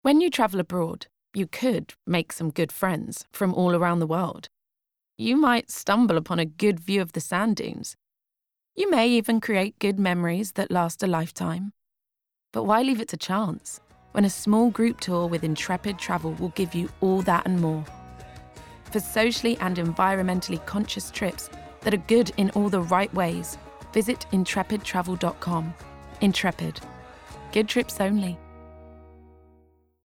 • It was the first time Intrepid Travel had advertised in the UK and they chose radio because of its ability to connect with people on an emotional level and because of radio’s strong ROI credentials.
• We used an authentic Moroccan music track to add to the sense of adventure and dynamism that a trip with Intrepid Travel brings you.
• We cast a voice over artist whose tone encapsulated the brand’s identity – i.e. fresh, slightly edgy yet humble.